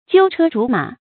鳩車竹馬 注音： ㄐㄧㄨ ㄔㄜ ㄓㄨˊ ㄇㄚˇ 讀音讀法： 意思解釋： 鳩車、竹馬：兒童玩具。借指童年 出處典故： 清 曹寅《集余園看梅同人限字賦詩追憶昔游有感而作》：「 鳩車竹馬 曾經處，鮐背龐眉識此生。」